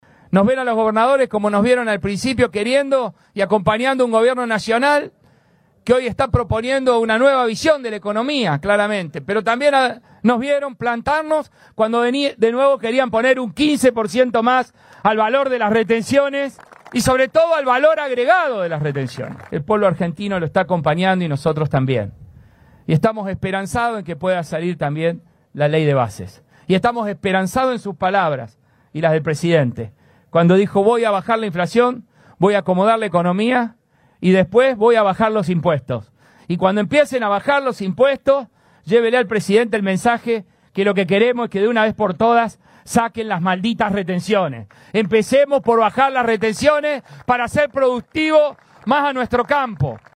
Los mandatarios de Santa Fe, Córdoba y Entre Ríos participaron este jueves del acto inaugural de la muestra Agroactiva, la exposición agropecuaria a cielo abierto más convocante de la Argentina y la región que se desarrolla hasta el 8 de junio en la ciudad de Amstrong.
El gobernador por Córdoba, Martín Llaryora apostó por la aprobación de la ley Bases y reclamó la quita de retenciones.
AGROACTIVA-GOB-CORDOBA-MARTIN-LLARYORA.mp3